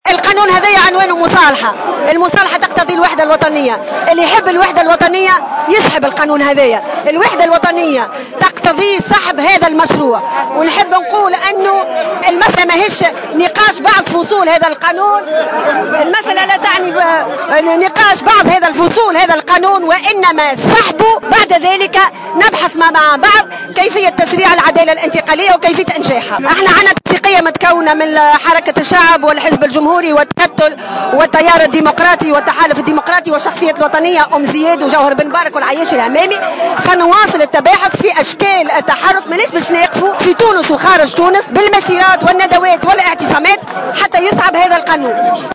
Maya Jeribi, leader au parti Républicain, a indiqué ce samedi 12 septembre 2015 au micro de Jawhara FM, que dans l’intérêt de l’union nationale, la loi de réconciliation doit être retirée.